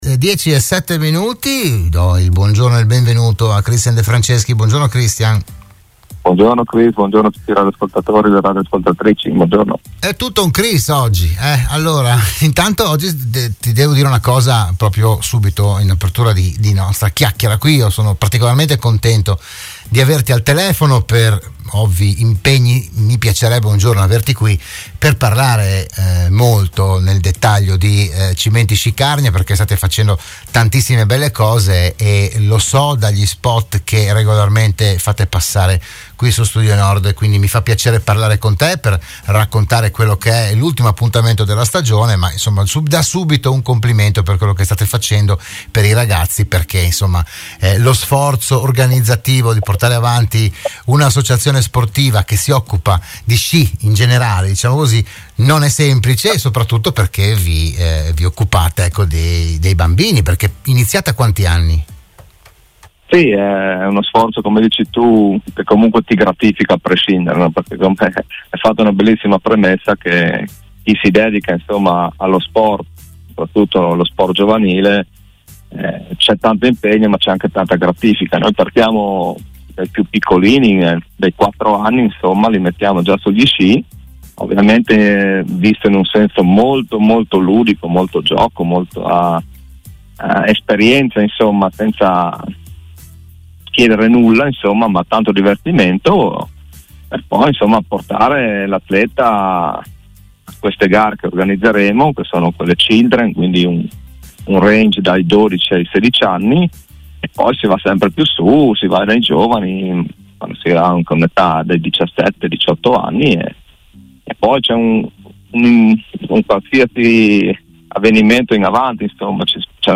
Dell’evento si è parlato a “RadioAttiva“, la trasmissione di Radio Studio Nord